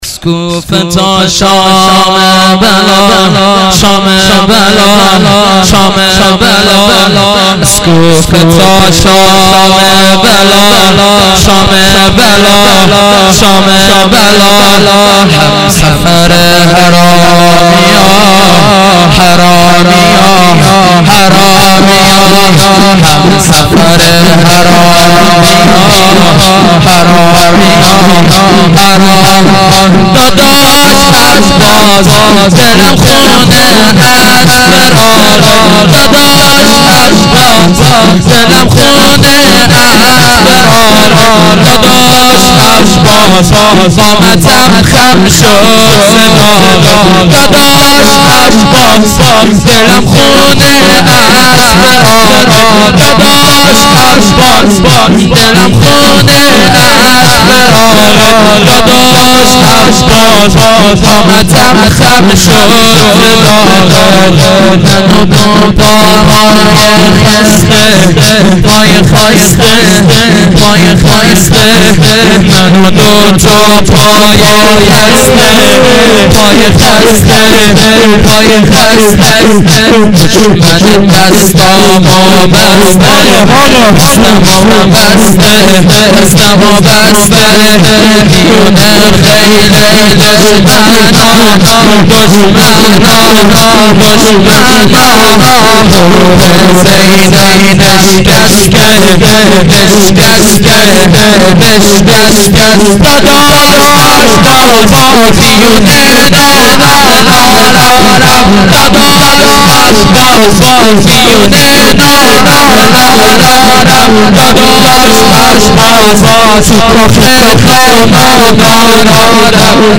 shahadat-emam-reza-92-shor-farsi-arabi.mp3